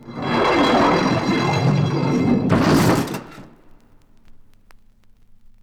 • portcullis closing.wav
Recorded from Sound Effects - Death and Horror rare BBC records and tapes vinyl, vol. 13, 1977.
portcullis_closing_dxw.wav